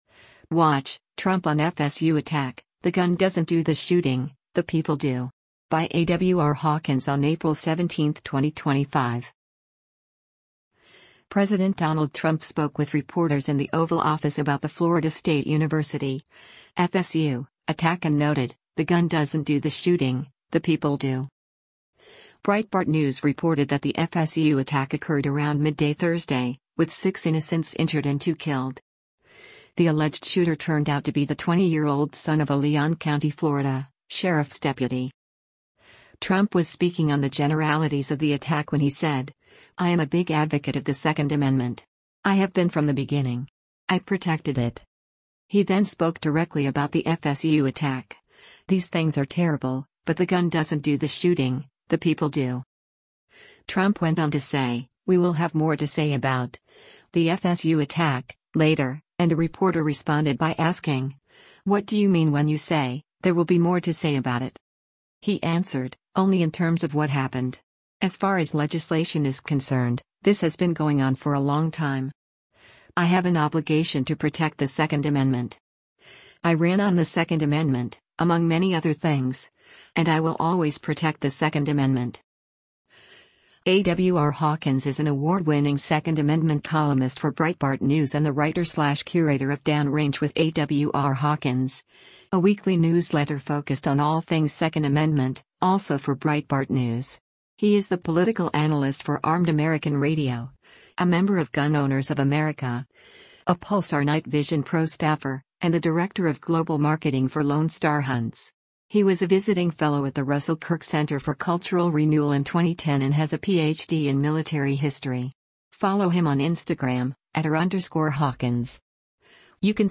President Donald Trump spoke with reporters in the Oval Office about the Florida State University (FSU) attack and noted, “The gun doesn’t do the shooting, the people do.”